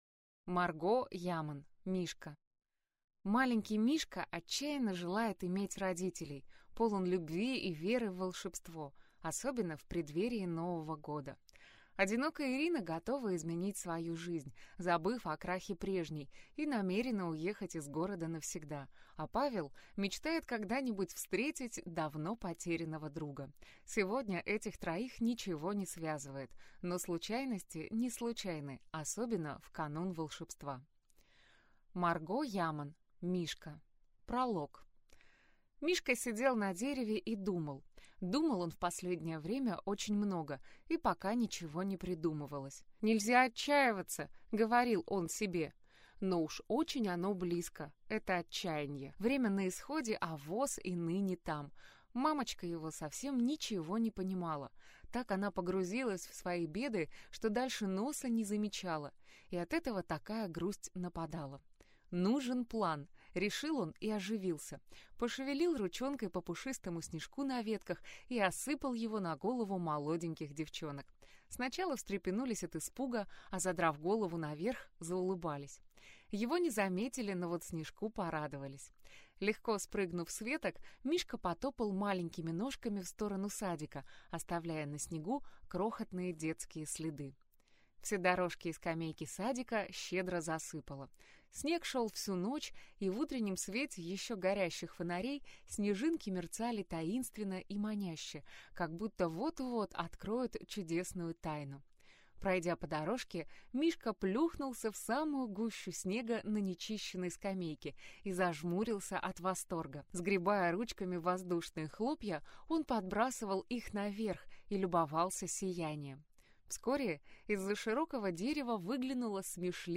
Аудиокнига Мишка | Библиотека аудиокниг